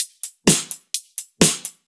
Index of /VEE/VEE2 Loops 128BPM
VEE2 Electro Loop 147.wav